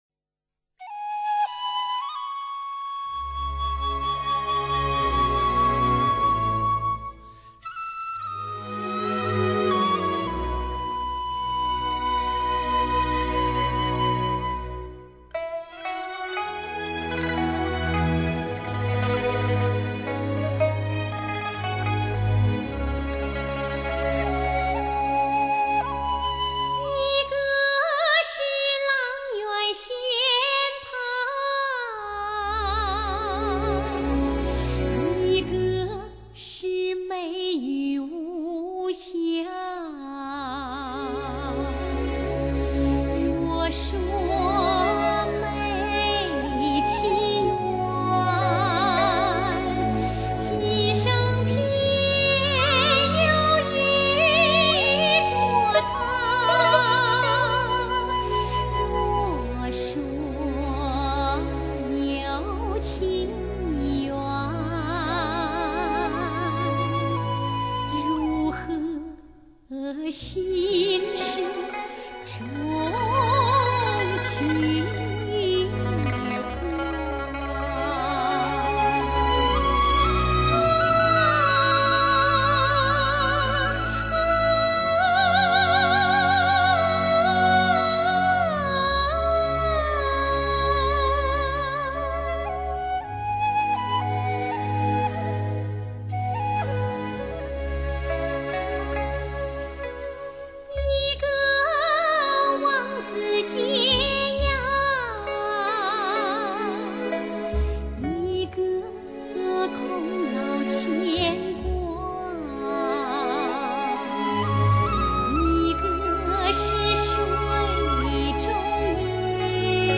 全球限量100支的B&K话筒，NEVE顶级多轨调音台，央视无以伦比的一号录音棚。